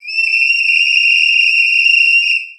一瞬にして駅のプラットフォームを活気づける、車掌の魂を宿した笛の音。